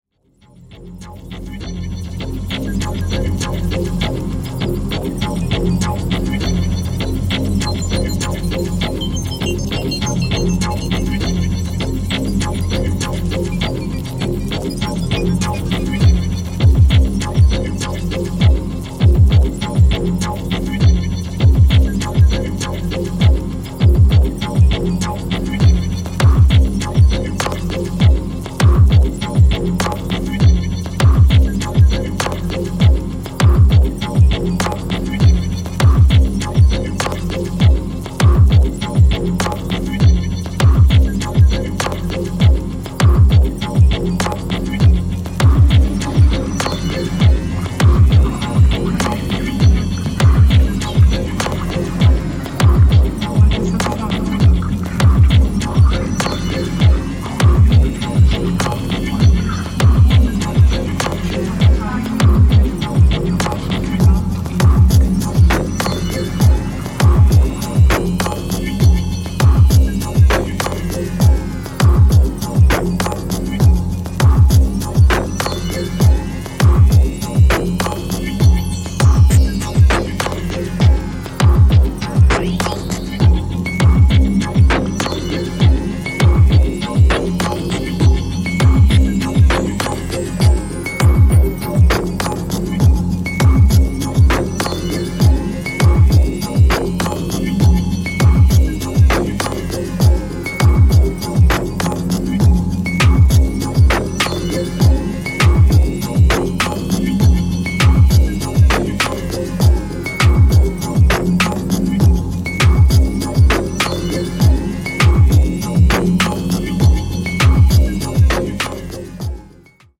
70~90’sのクラシカルなシンセミュージックの全般に影響を受けていそうな内容で
100BPMのダビーなアブストラクトチューン